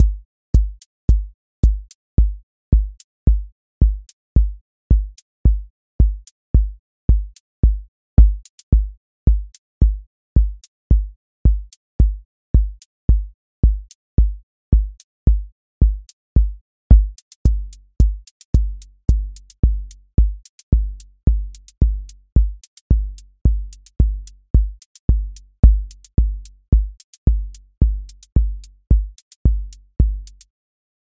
QA Listening Test house Template: four_on_floor
steady house groove with lift return
• voice_kick_808
• voice_hat_rimshot
• voice_sub_pulse